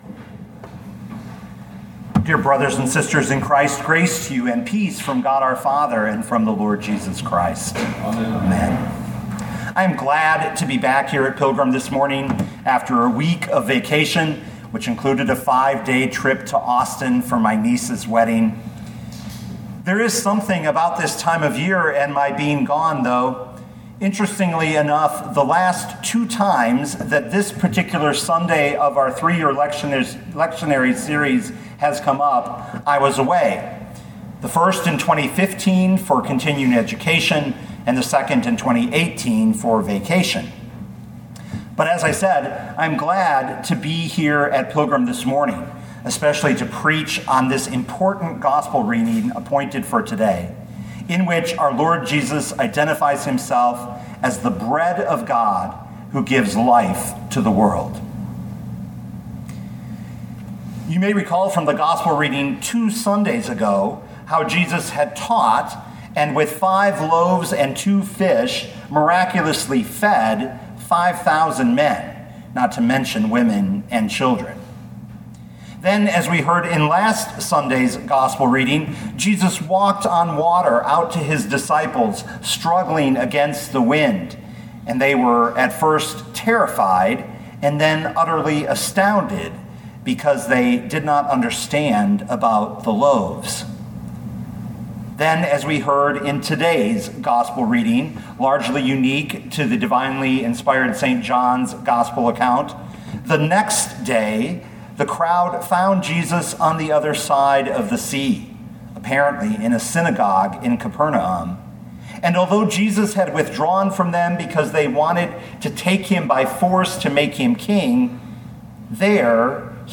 2021 John 6:22-35 Listen to the sermon with the player below, or, download the audio.